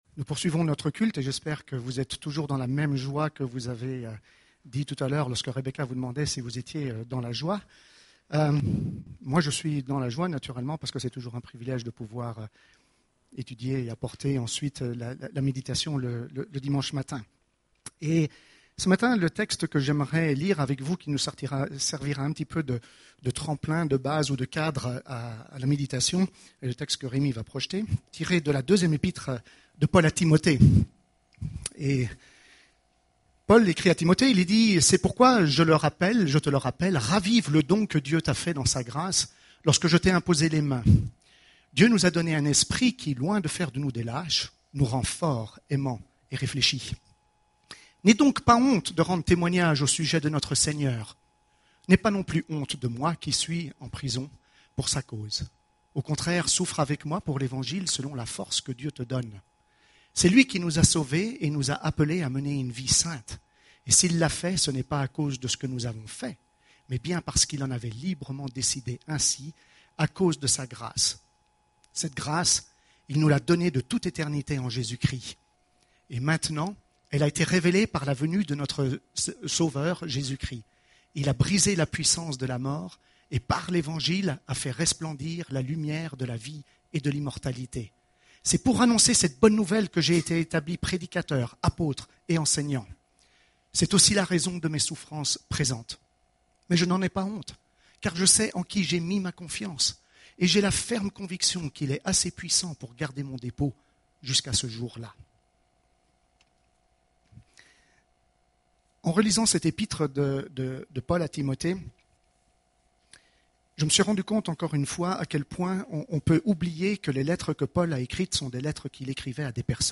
Culte du 13 juillet